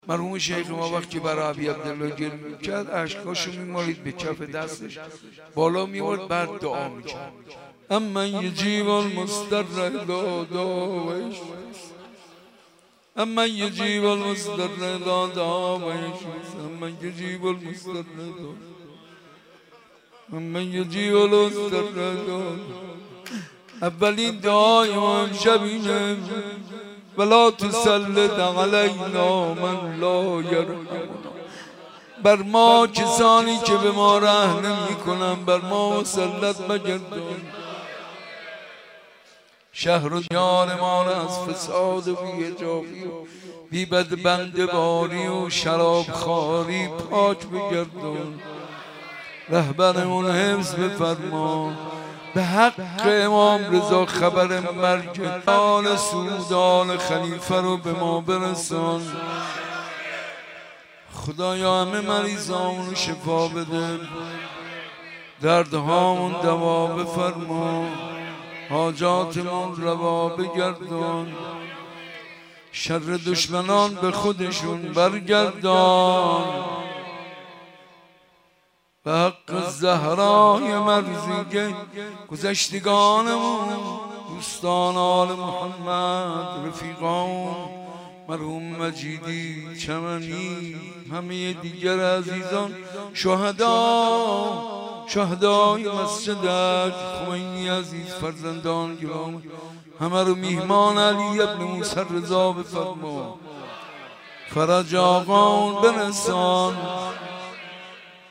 جشن میلاد امام رضا(ع)/ حسینیه بیت الزهرا(س)